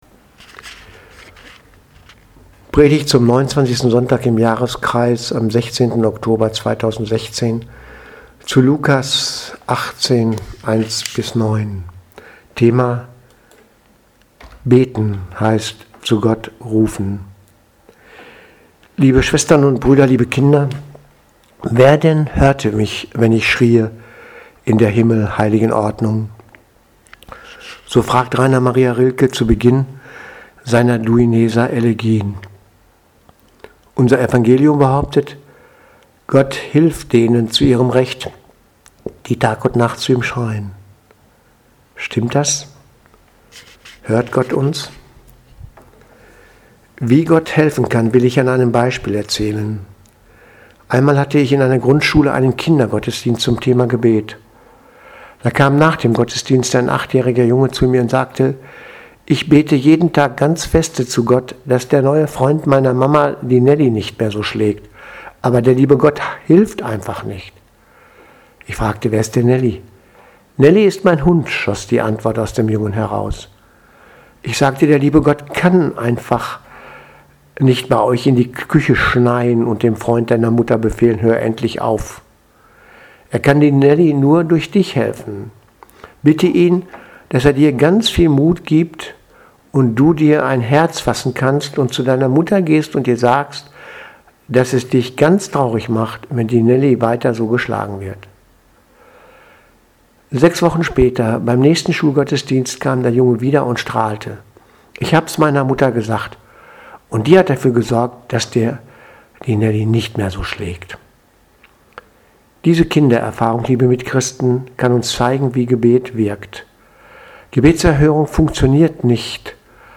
Predigt vom 16.10.2016 – zu Gott rufen
Predigt zum 29. Sonntag im Jahreskreis 2016-10-16